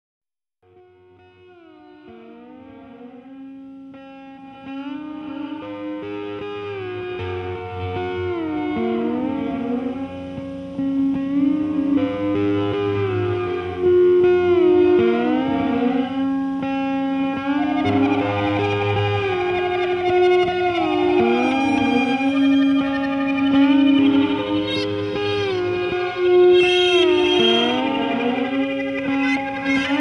Psicadélia exacerbada, sem pretensões.